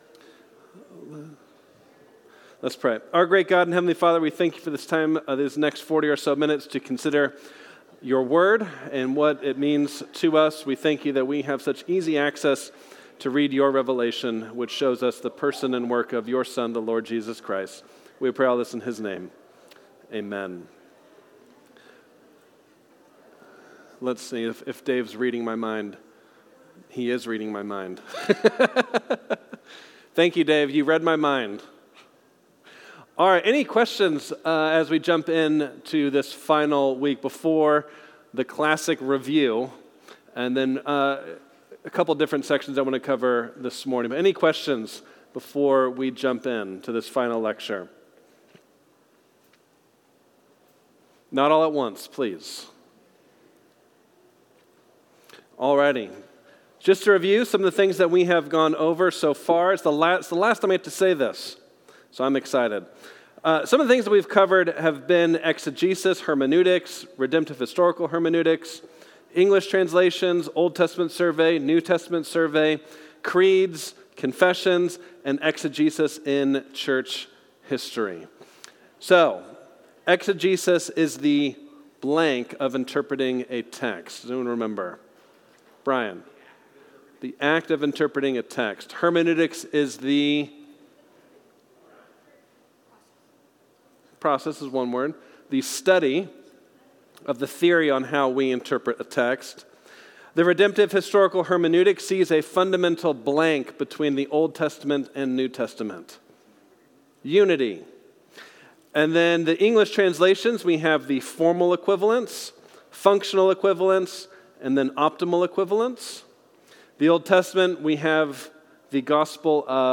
New Life Sunday School | New Life Presbyterian Church of La Mesa